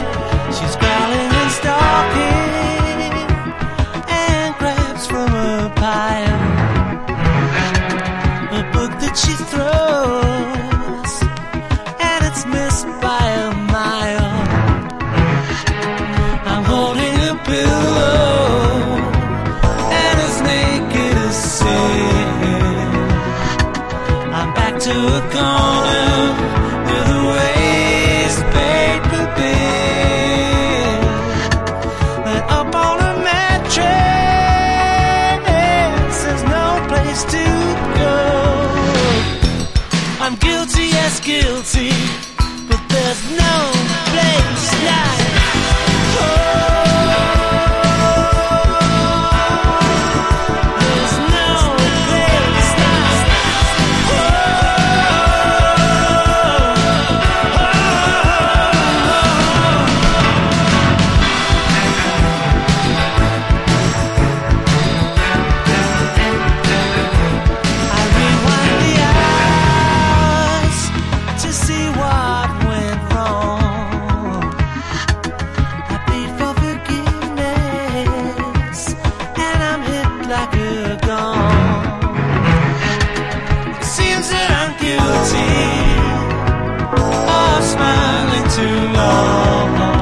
NWディスコチックなA1や現行のインディーソウルファンにも届きそうなA2など、サウンドとメロの美しさが安定してます。